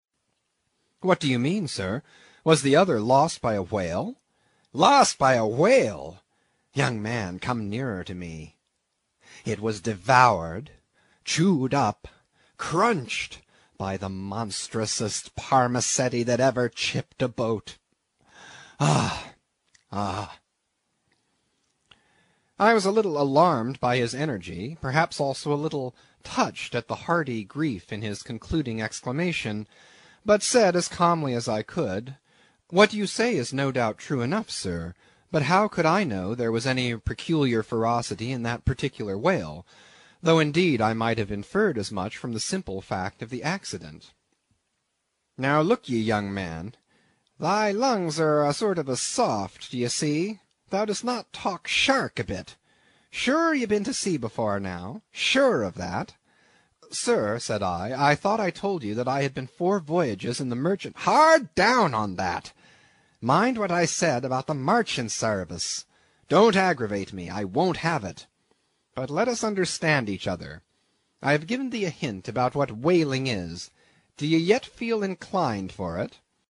英语听书《白鲸记》第157期 听力文件下载—在线英语听力室